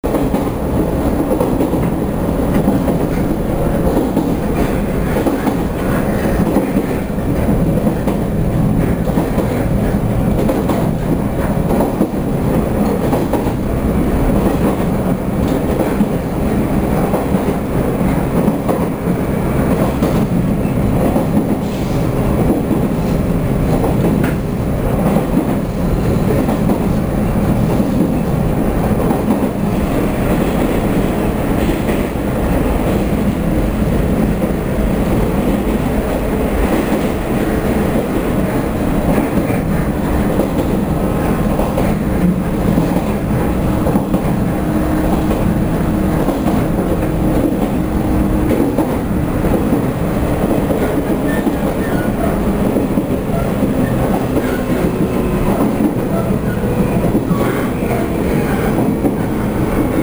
※踊り子号走行音→mp3形式約1.87MB。読みながらお聞きになると、臨場感が増します。